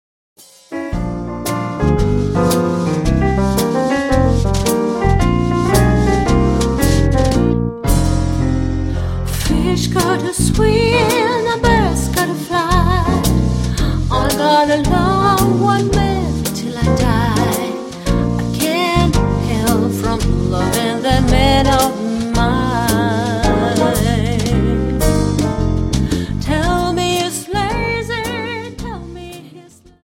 Dance: Slowfox 29